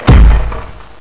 crate1.mp3